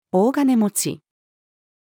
大金持ち-female.mp3